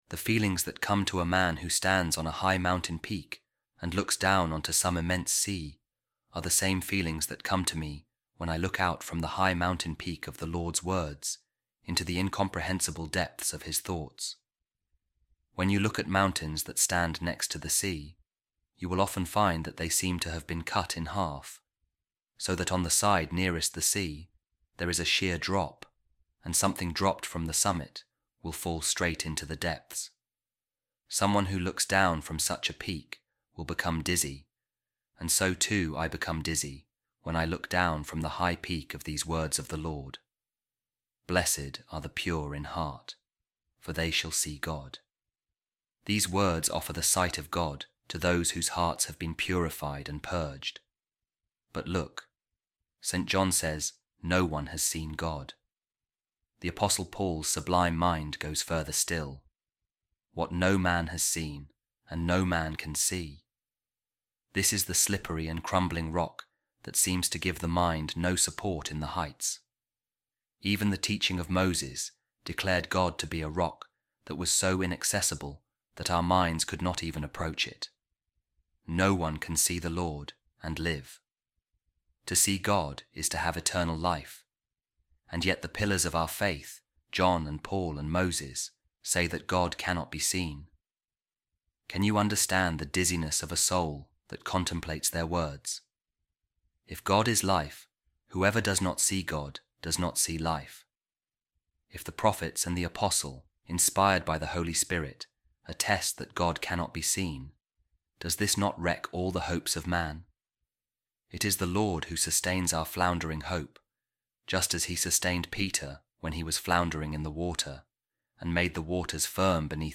A Reading From The Homilies Of Saint Gregory Of Nyssa On The Beatitudes | God Is Like An Inaccessible Rock